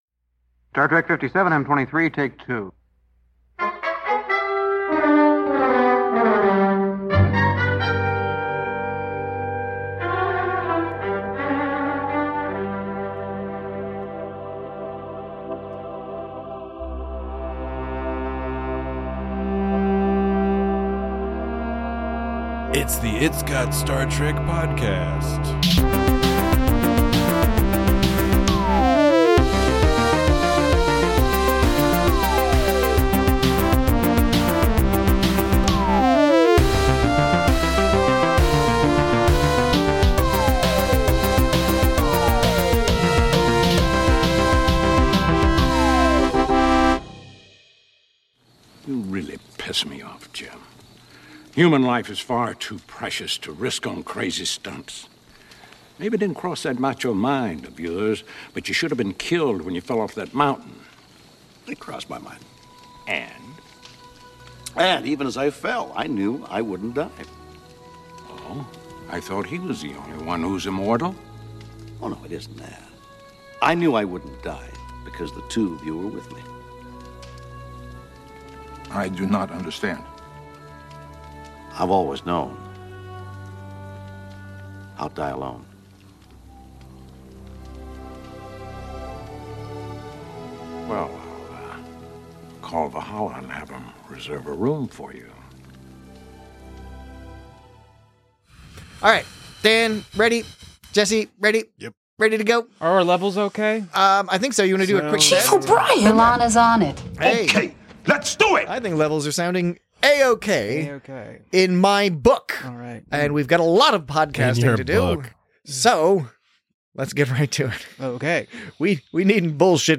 One of Spock's siblings is causing a ruckus. Join your combative hosts as they debate the quality and value of this much-maligned - yet beloved by many - entry into the TOS film canon.